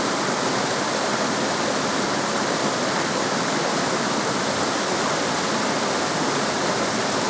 sfx_waterall.wav